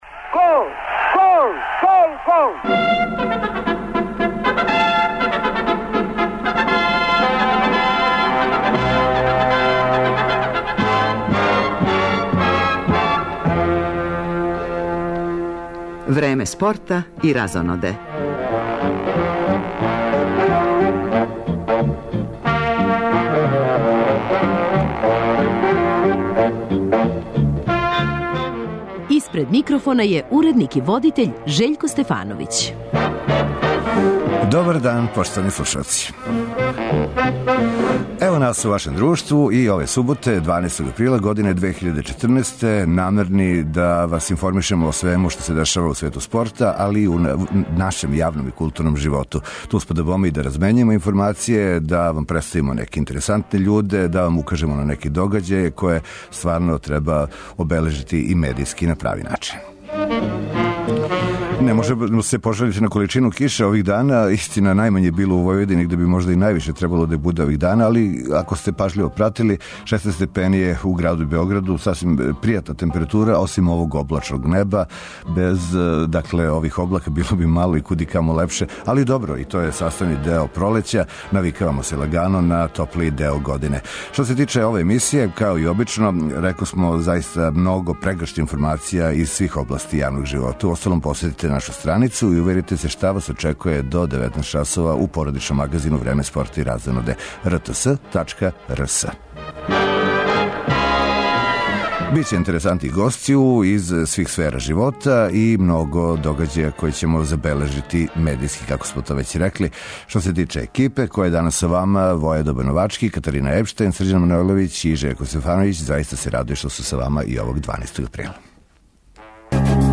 Током емисије пратићемо дешавања на фудбалској утакмици Јавор-Партизан и рукометном мечу Војводина-Партизан. Представљамо новог суперлигаша у одбојци - први пут у историји клуба, то су момци из ОК Нови Пазар, а ту је и прича о рукометашицама Железничара из Инђије, као и друге актуелности из света спорта, јавног и културног живота.